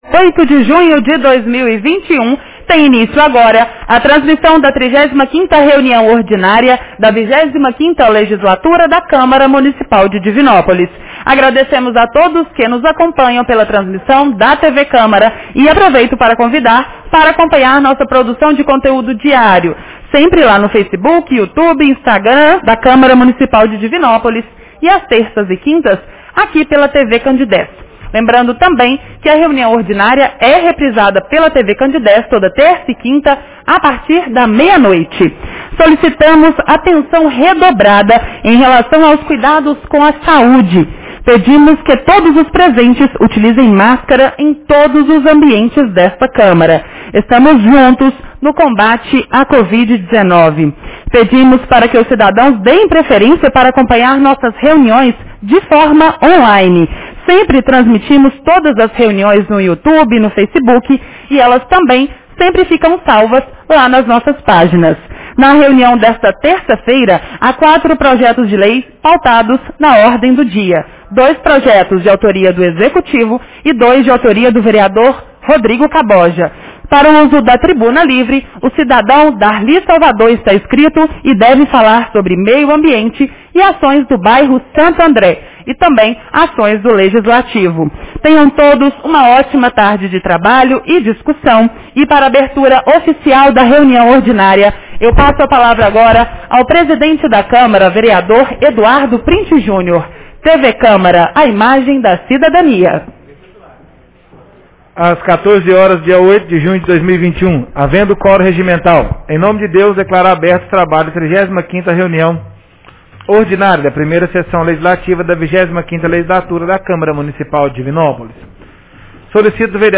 Reunião Ordinária 35 de 08 de junho 2021